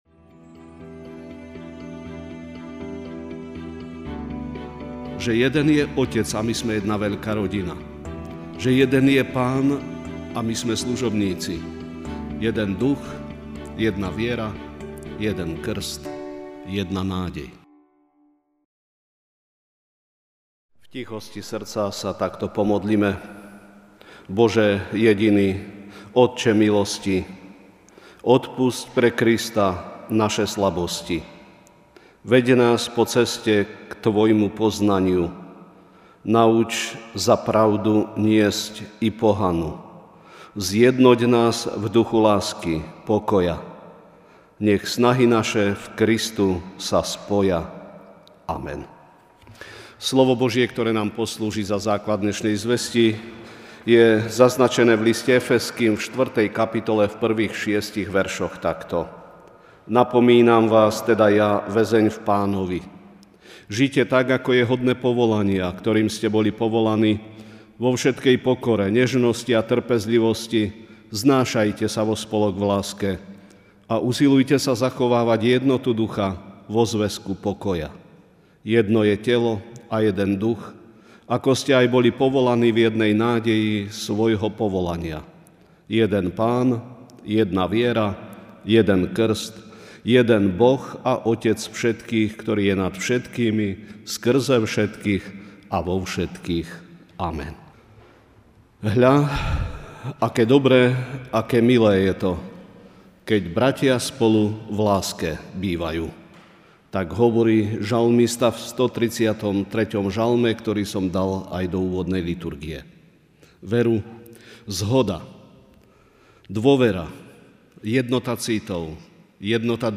Večerná kázeň: Jednota (Efezským 4,1-6)